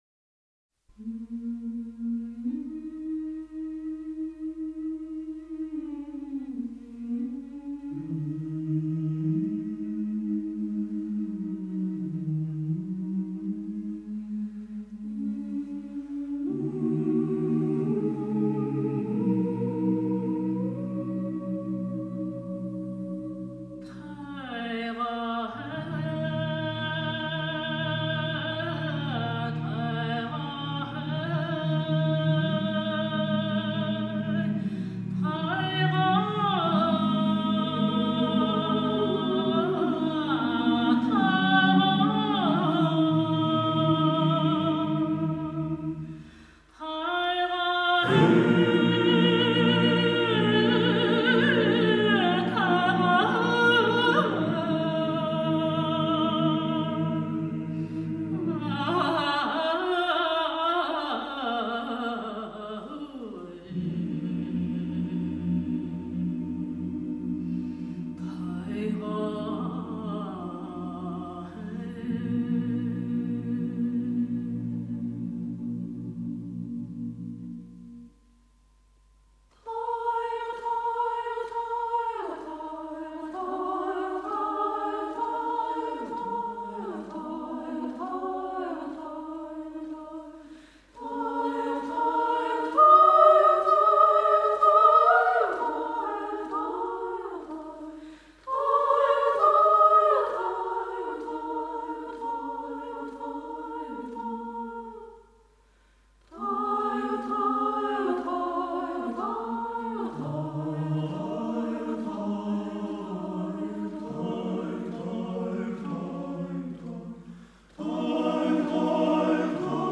蒙古民族是一个质朴豪爽的民族，蒙古民歌有着优美的旋律，独特的韵味，歌中时时透射出蒙古人胸襟的开阔。
长调中特殊的发声技巧称作“诺古拉”，即波折音，类似颤音，对形成蒙古族长调独特风格有重要作用。
阿拉坦其其格擅长蒙古族长调民歌，她的演唱音色浑厚明亮，音域宽广，气息充沛，行腔委婉自如，典雅华丽，深受广大听众喜爱。